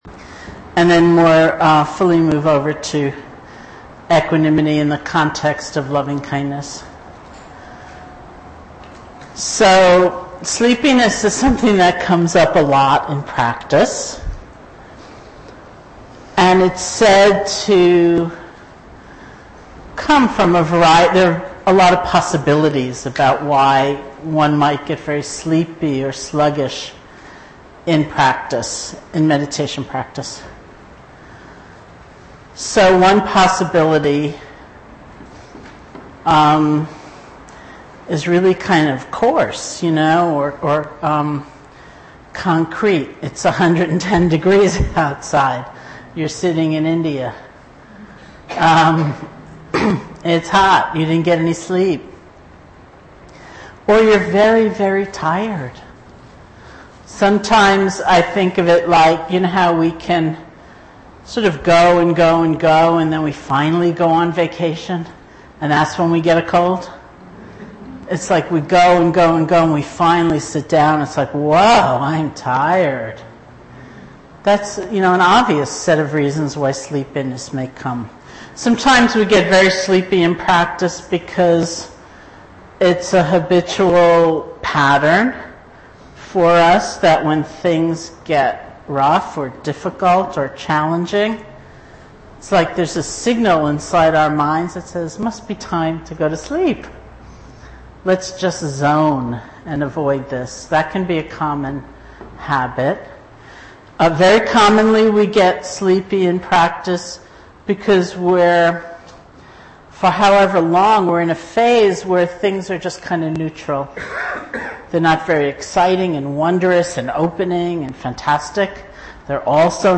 Teacher: Sharon Salzberg Date: 2010-07-24 Venue: Seattle Insight Meditation Center Series [display-posts] TalkID=828